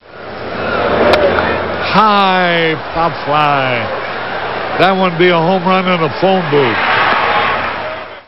TV Harry Caray Pop Fly
Category: Sports   Right: Personal